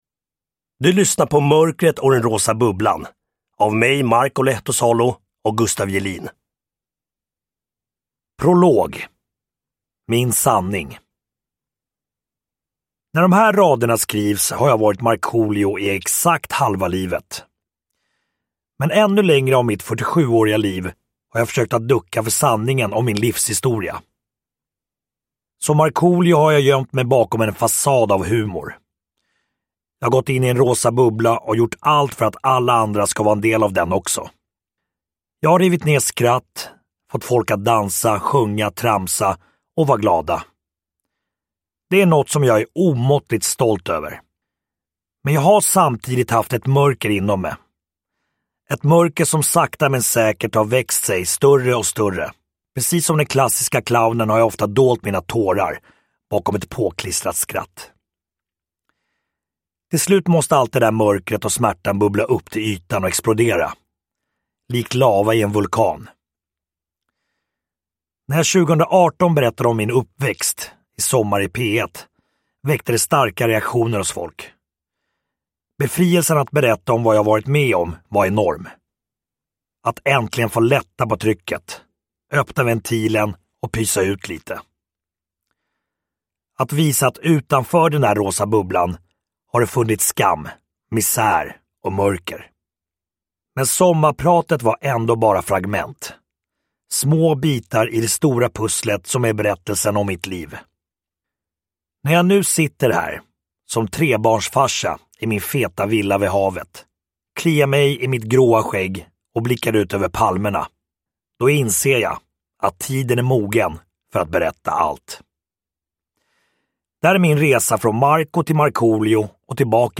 Markoolio, mörkret och den rosa bubblan – Ljudbok – Laddas ner
Uppläsare: Marko Lehtosalo